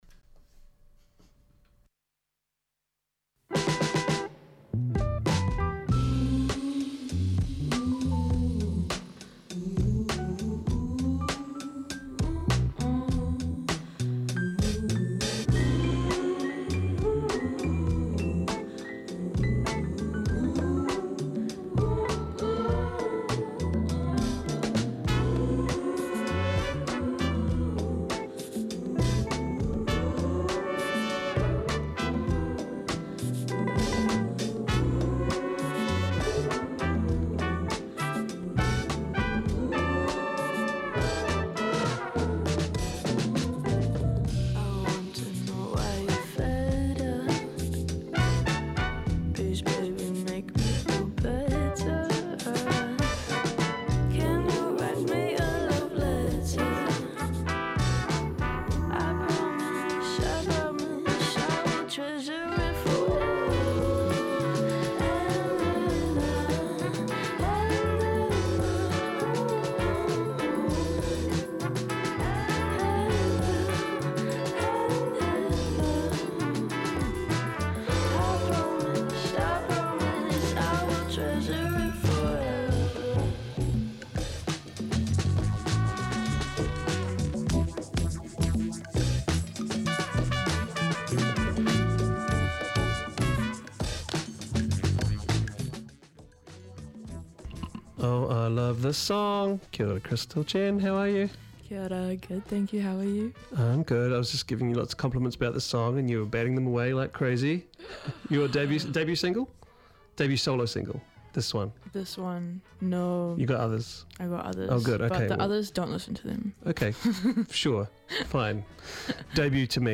Guest Interviews
Guest Interview w/ deary: 1 April, 2026